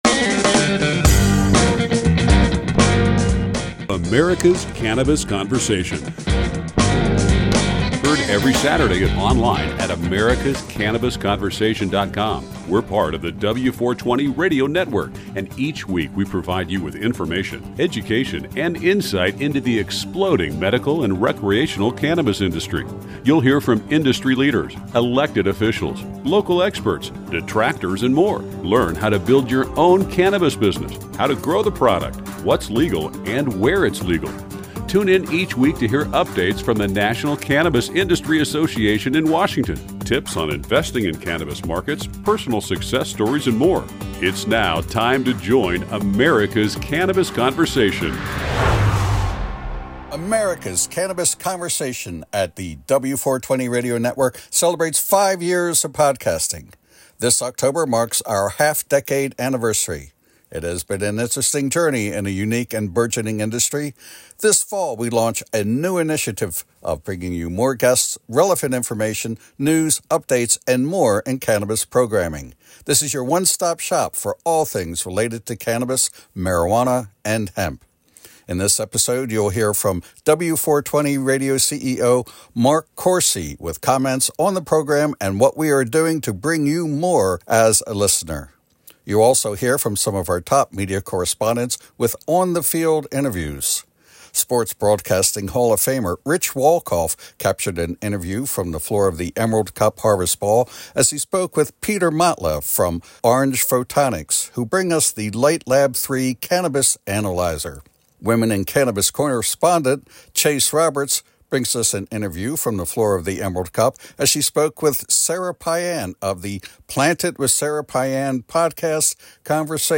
S6.E02. 5-Year Anniversary Show.